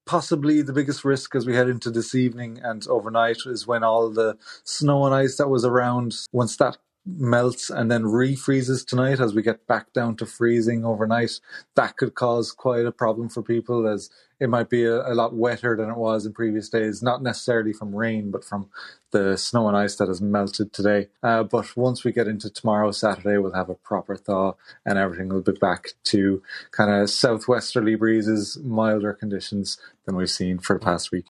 Forecaster